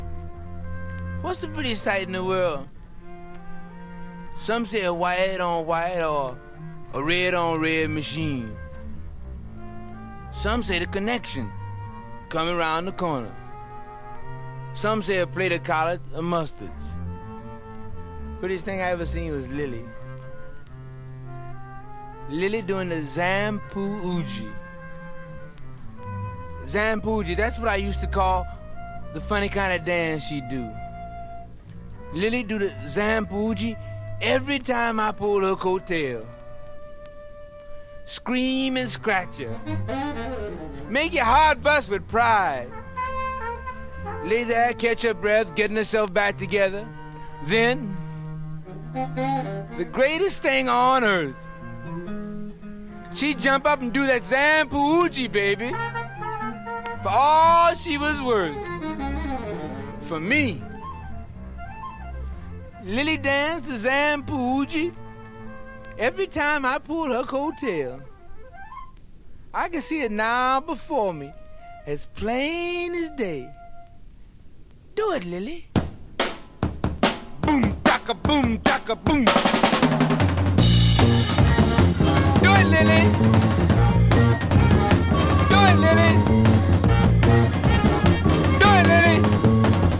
サイケデリックなフルートが印象的なレア・グルーヴ・ポエトリー。
所によりノイズありますが、リスニング用としては問題く、中古盤として標準的なコンディション。